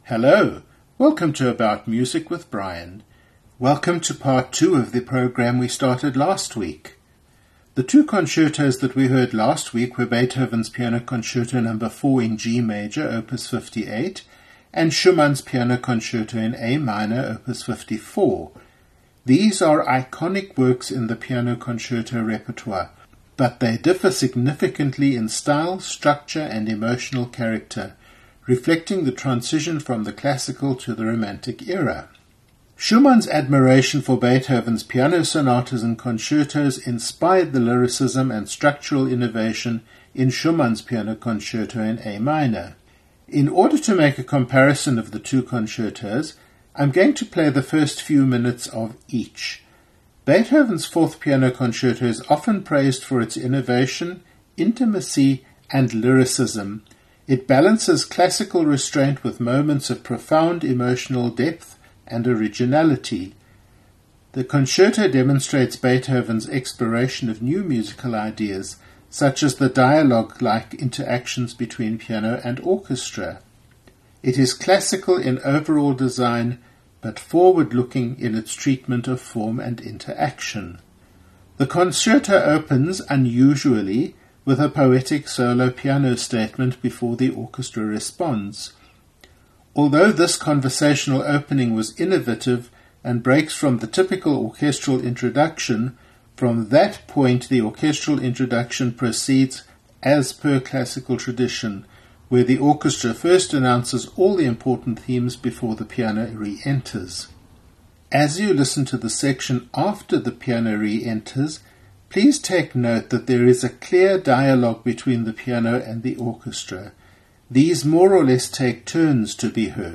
Today we listen to movements of Beethoven’s Piano Concerto number 4, and Schumann’s only Piano Concerto and we explore what it is about these concertos that is so typical of their periods, and how Beethoven’s influence is seen in Schumann’s concerto.